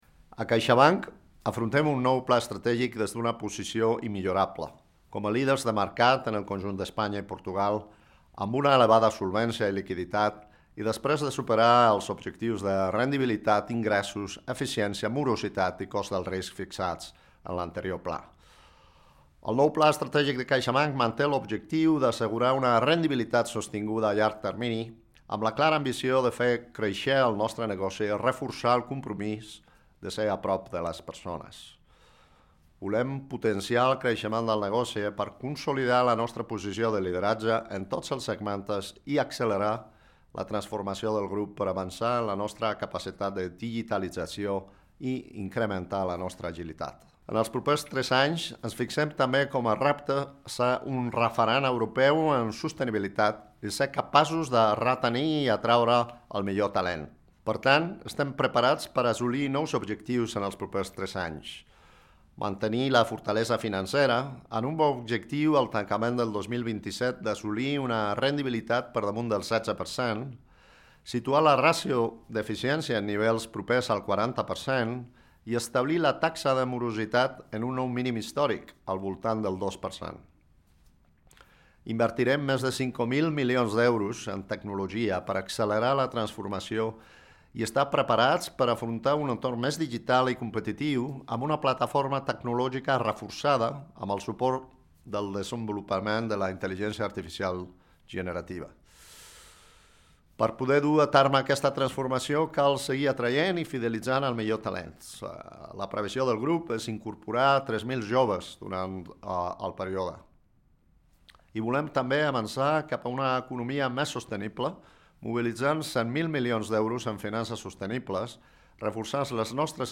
Àudio del CEO de CaixaBank, Gonzalo Gortázar